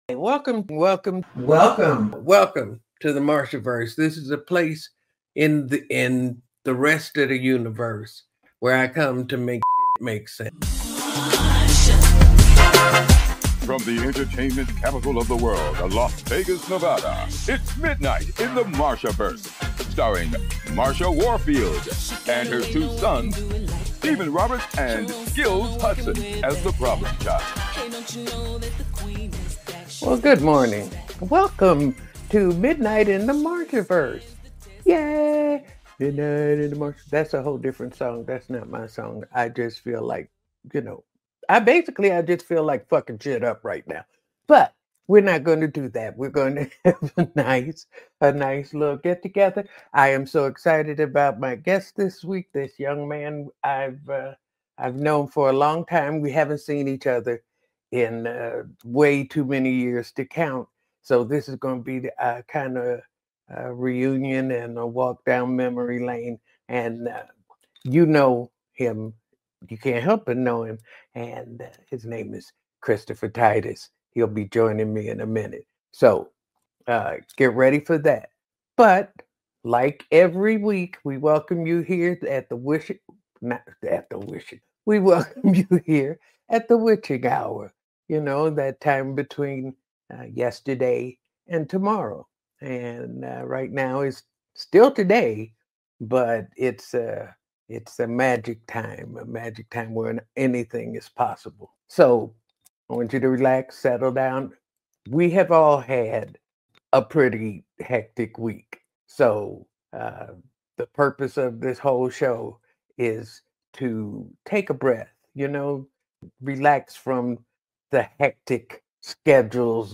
This week on Midnight in the Marshaverse, Marsha Warfield welcomes comedian, actor, and writer Christopher Titus for a powerful conversation on surviving showbiz, the craft of comedy, and navigating today's chaotic political climate.